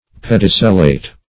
Pedicellate \Ped"i*cel`late\, a. Having a pedicel; supported by a pedicel.